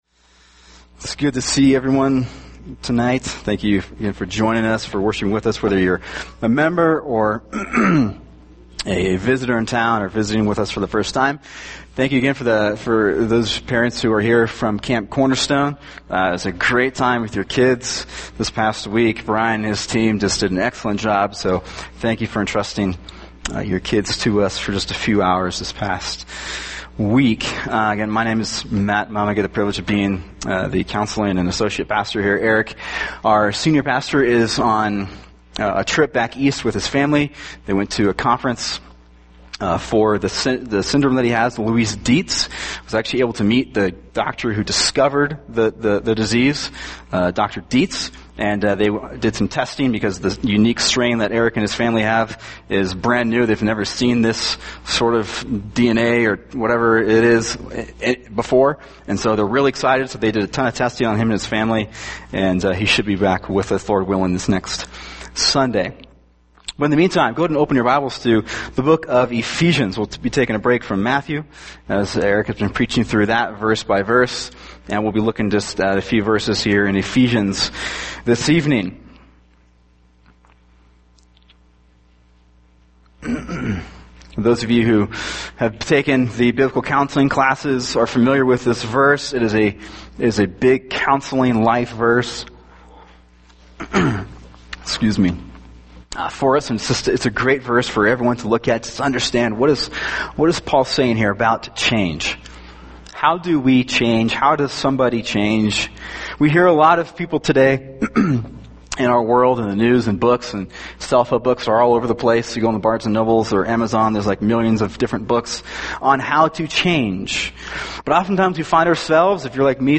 [sermon] Ephesians 4:22-32 – A Changed Life | Cornerstone Church - Jackson Hole